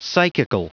Prononciation du mot psychical en anglais (fichier audio)
Prononciation du mot : psychical